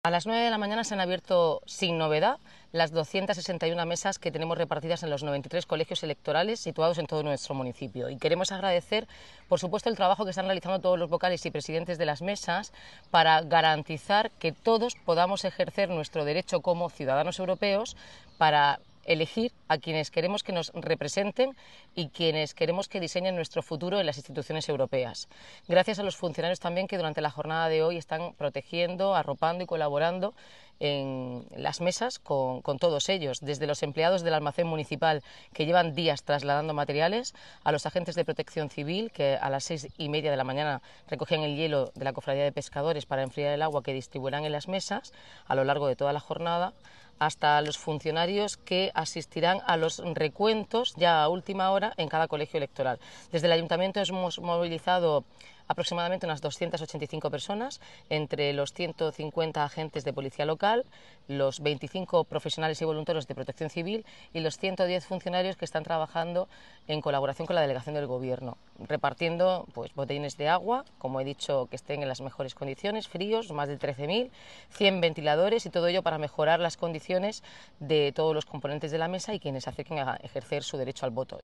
Enlace a Declaraciones de la alcaldesa Noelia rroyo